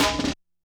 percussion03.wav